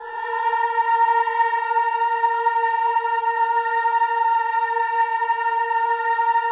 VOWEL MV14-R.wav